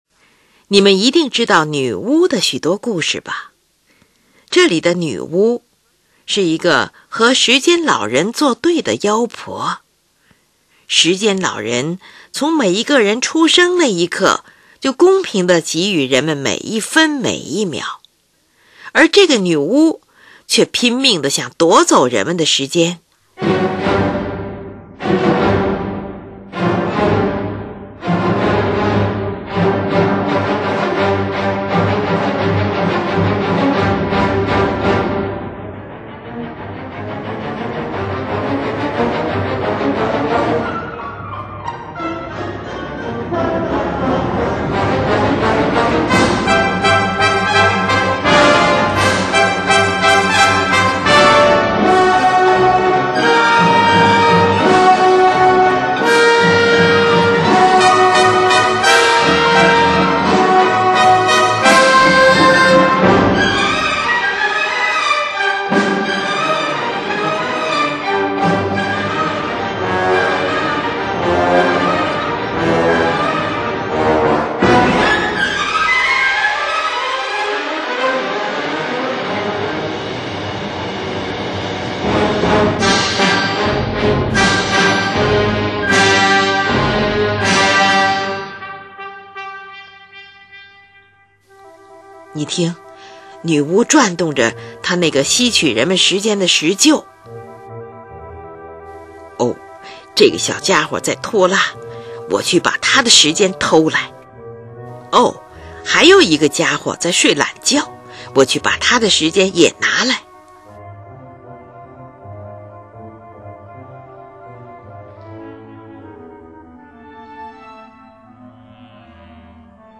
你听，三个小号和法国号奏出的旋律就是妖婆。